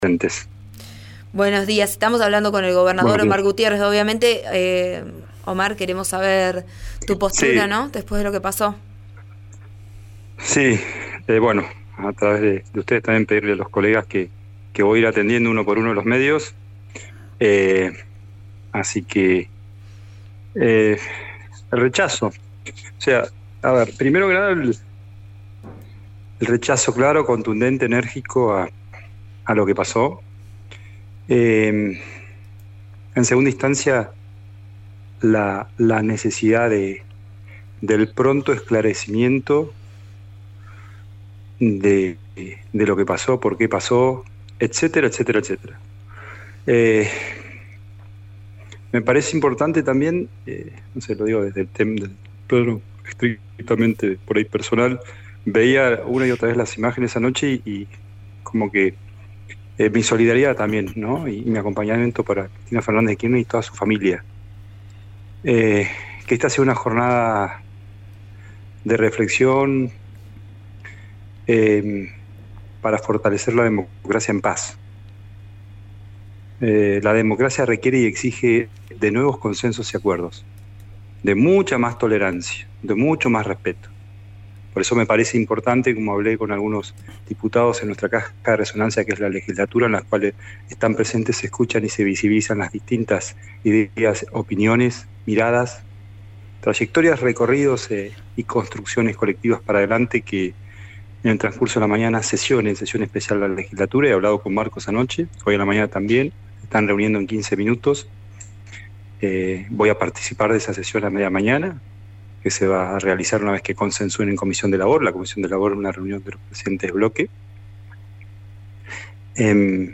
En el caso del gobernador Gutiérrez dialogó con Vos a Diario RN Radio y si bien no encuadró el hecho de esa manera, expresó su solidaridad y acompañamiento a la vicepresidenta y a su familia.